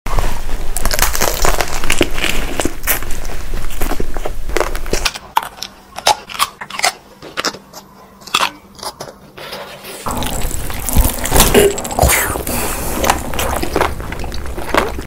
Random mukbang Eating Sounds ASMR sound effects free download
Food mukbang Korean Girls Eating Sounds